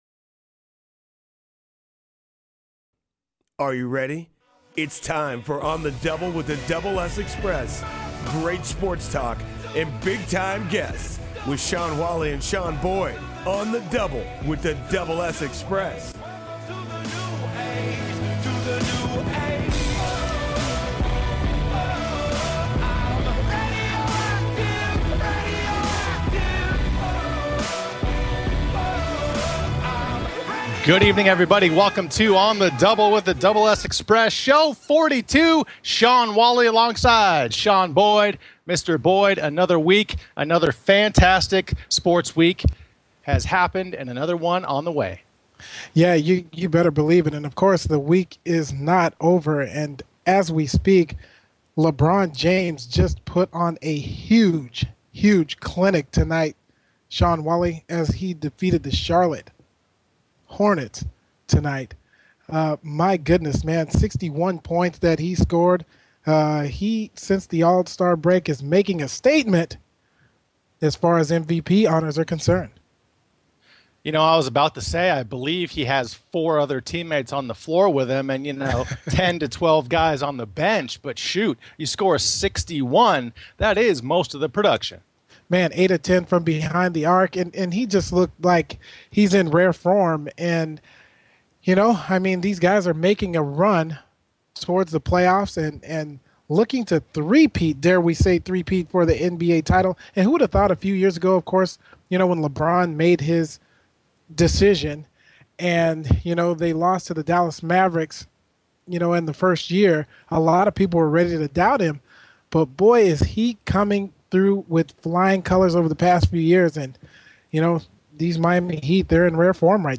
Guest: Drew Bledsoe